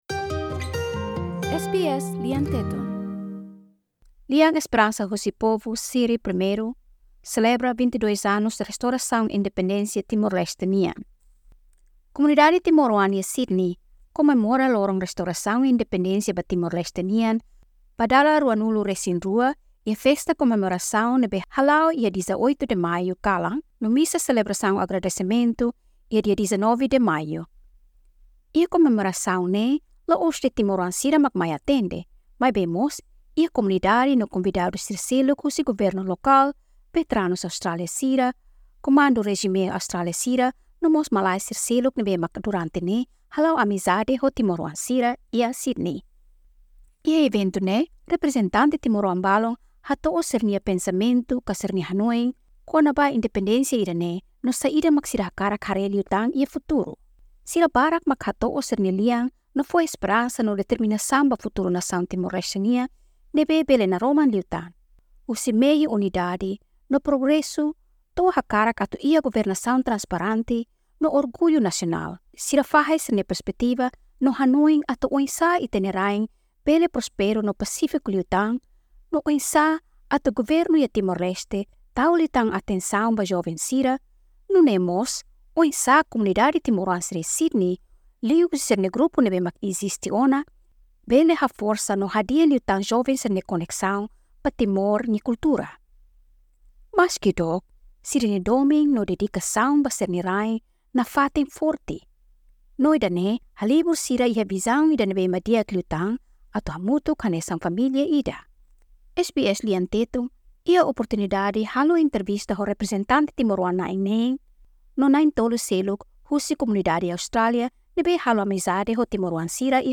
Iha eventu ne'e, reprezentante Timor-oan balun hato'o sira halo intervista ho Timor-oan balun kona-ba sira nia hanoin ka perspetiva kona-ba independensia ne'e. no saida mak sira hare iha futuru.